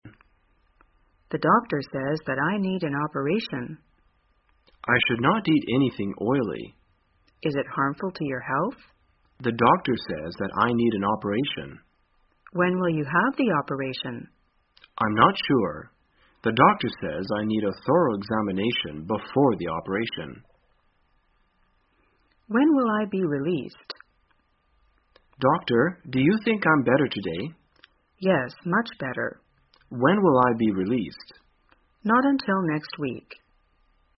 在线英语听力室生活口语天天说 第181期:怎样谈论手术与出院的听力文件下载,《生活口语天天说》栏目将日常生活中最常用到的口语句型进行收集和重点讲解。真人发音配字幕帮助英语爱好者们练习听力并进行口语跟读。